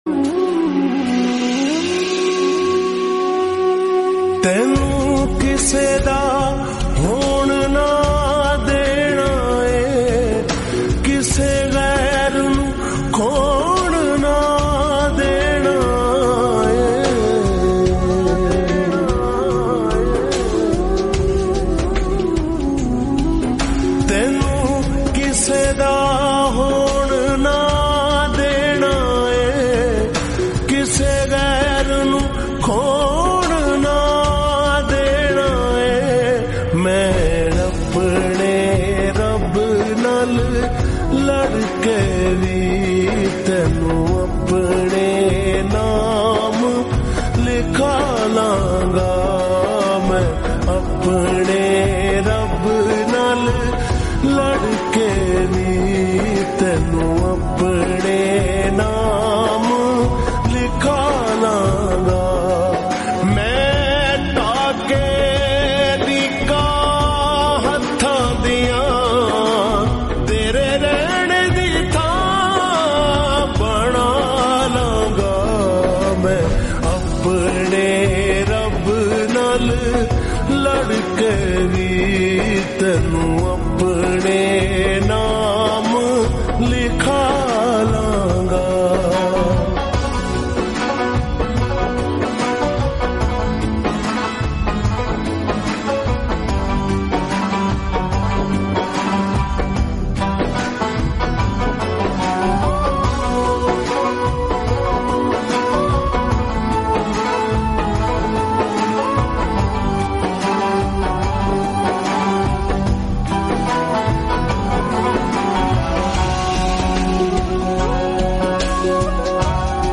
Full Song Slowed And Reverb
Most Heart Touching Song .